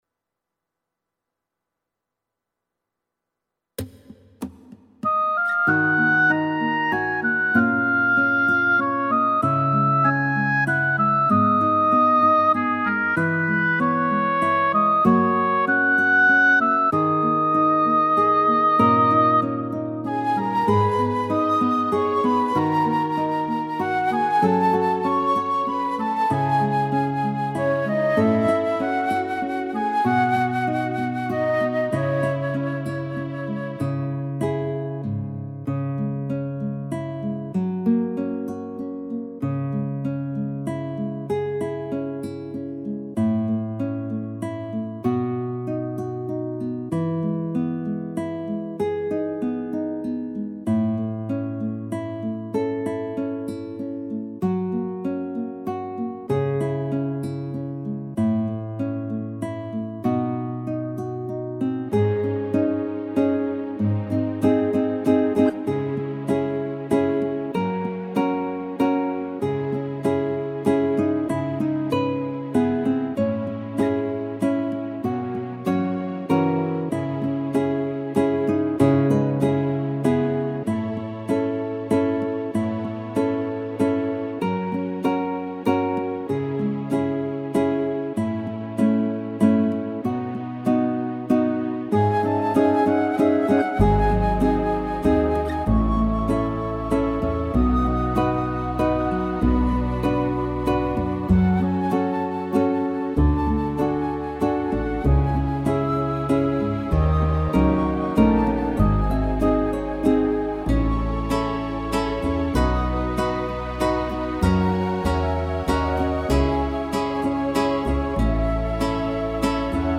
•   Beat  02.
(C#m) 4:20